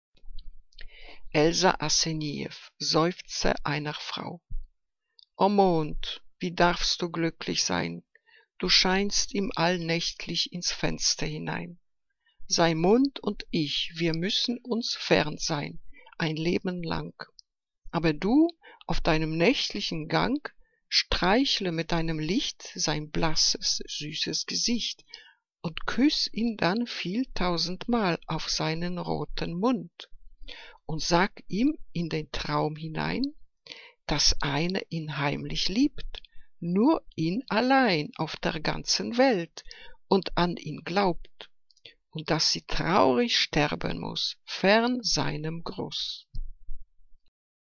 Liebeslyrik deutscher Dichter und Dichterinnen - gesprochen (Elsa Asenijeff)